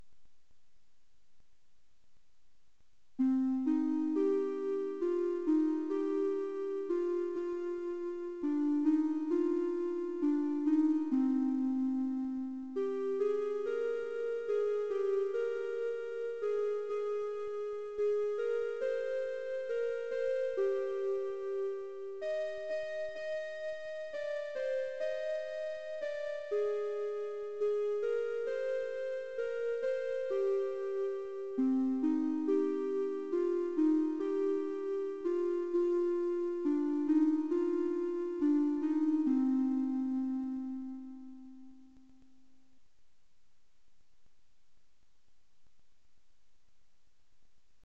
演奏1 mp3